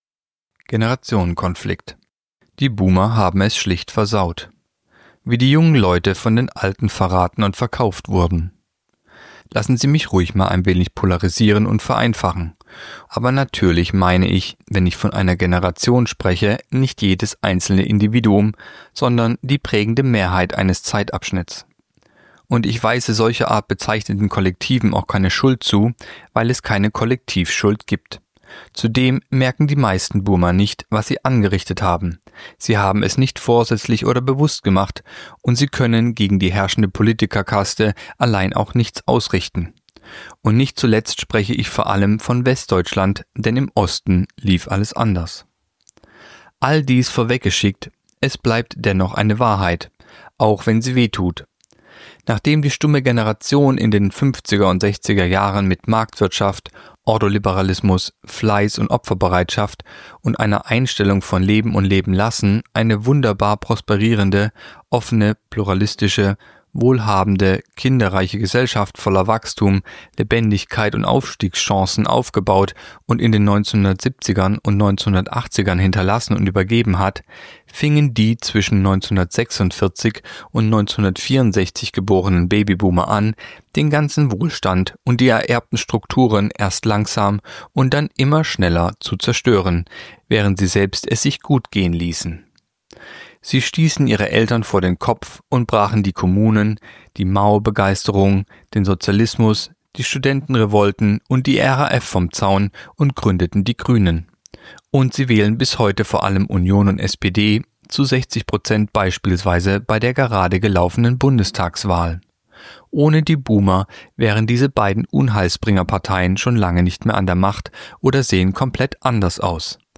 Artikel der Woche (Radio)